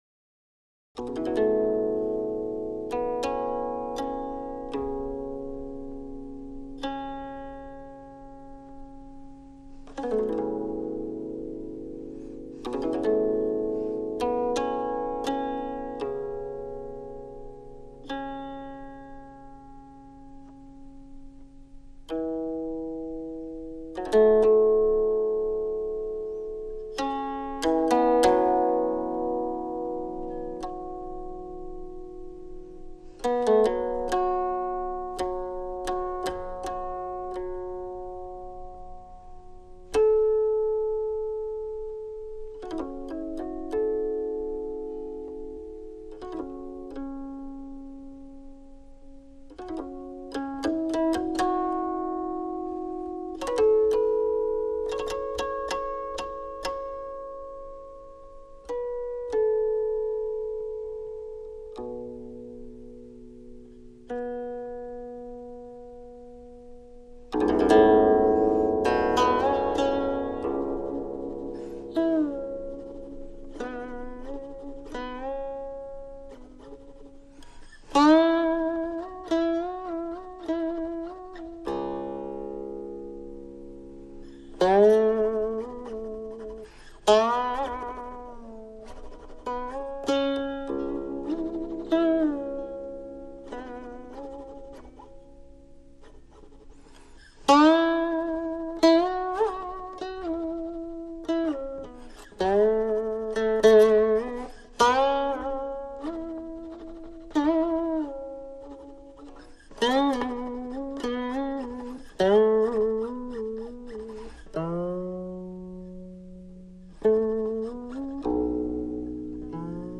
明代无名蕉叶琴